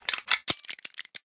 repair2.ogg